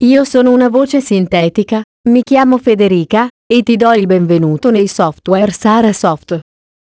E' la pronuncia di messaggi con voce umana sintetica, ad esempio "Benvenuto e buon lavoro" all'apertura del programma, "Confermi la cancellazione ?" in caso di richiesta di cancellazione di un dato da un archivio, eccetera.
testo pronunciato con la voce "Federica"
esempio-federica.wav